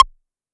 Popcorn synth.wav